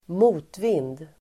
Uttal: [²m'o:tvin:d]